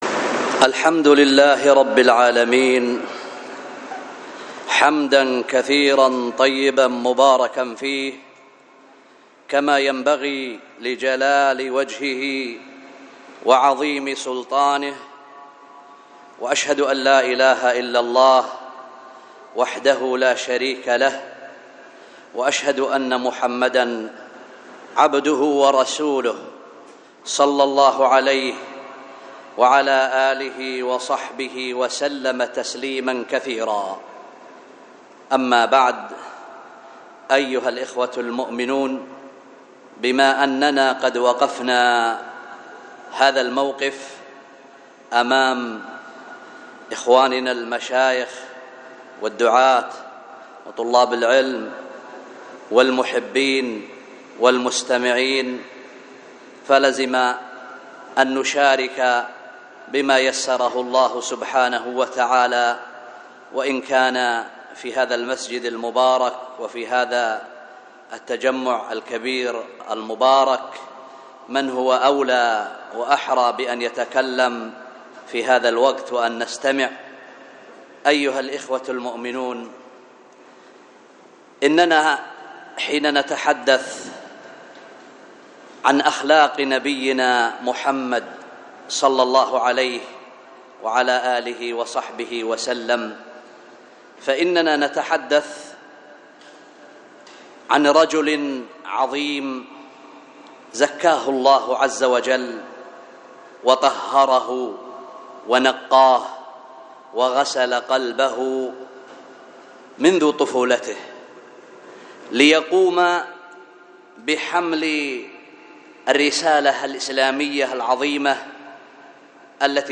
الخطبة
وكانت بمسجد التقوى بدار الحديث بالشحر